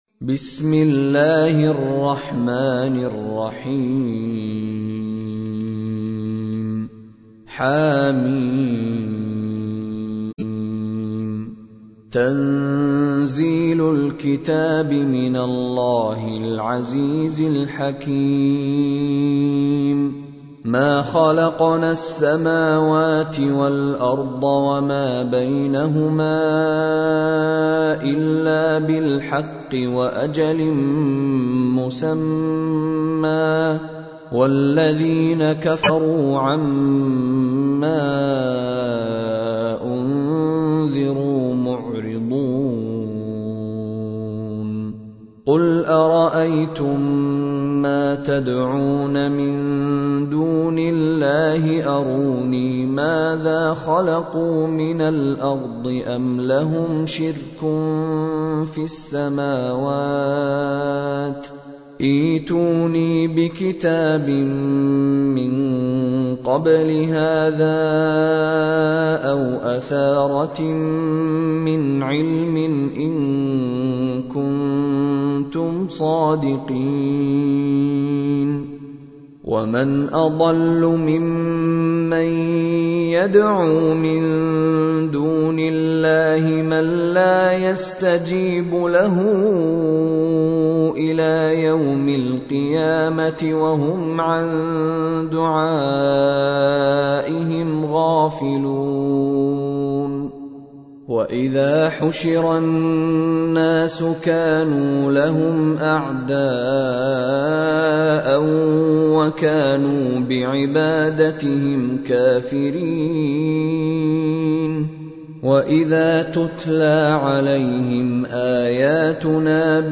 تحميل : 46. سورة الأحقاف / القارئ مشاري راشد العفاسي / القرآن الكريم / موقع يا حسين